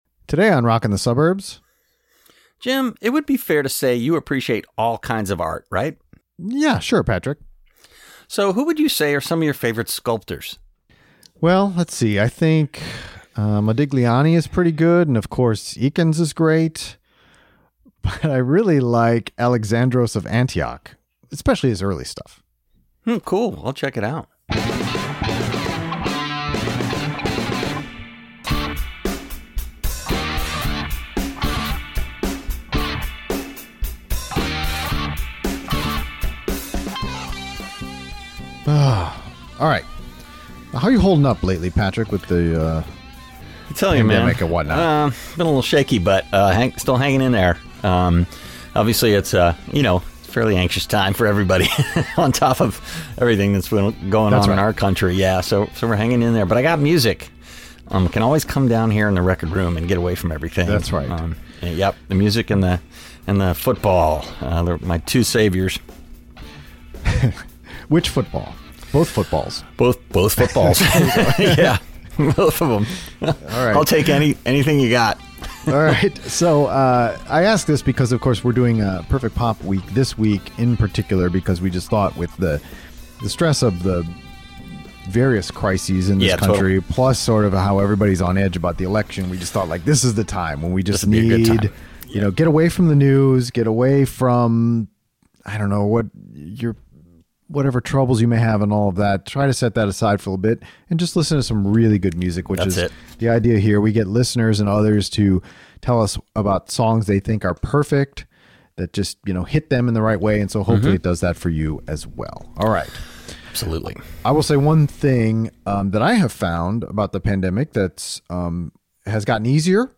We take two listener calls on this Perfect Pop episode.